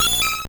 Cri d'Hypotrempe dans Pokémon Or et Argent.